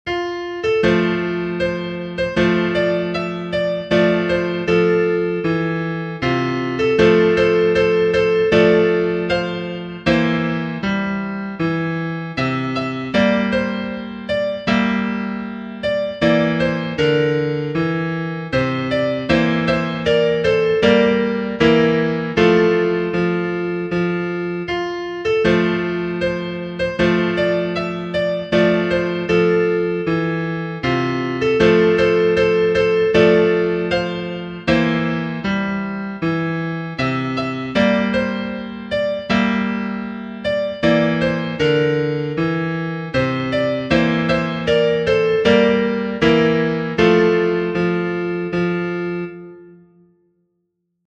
Genere: Sociali e Patriottiche